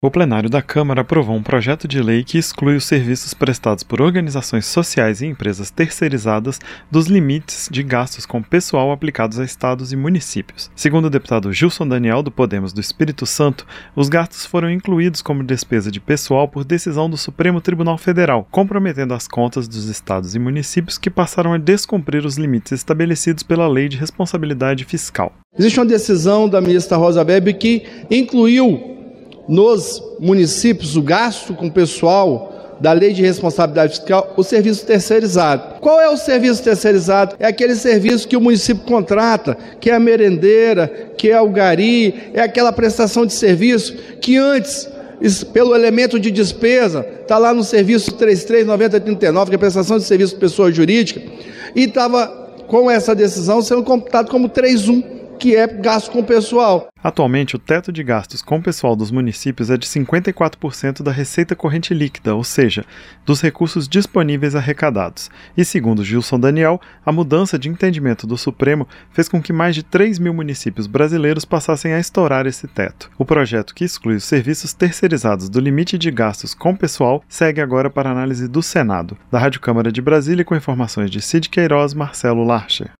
Câmara aprova projeto que exclui terceirização de limite de gastos com pessoal - Radioagência